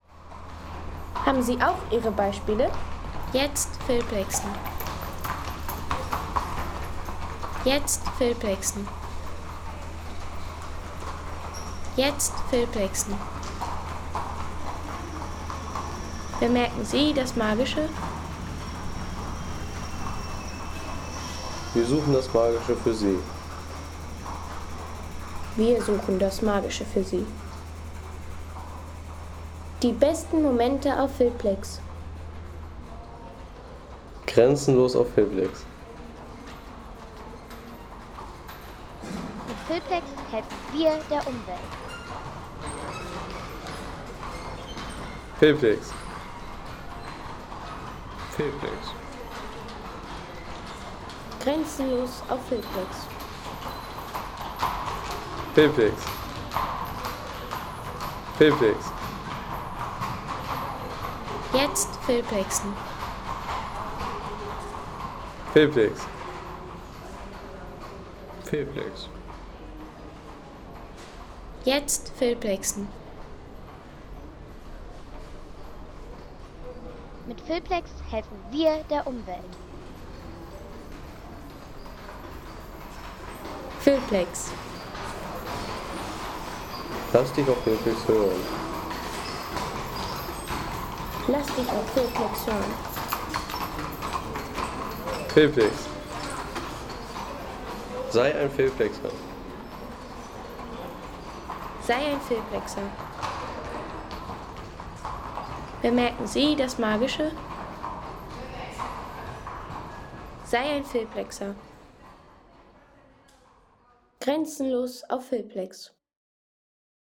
Diese atmosphärische Tonaufnahme fängt die klangvolle Passage einer ... 8,50 € Inkl. 19% MwSt.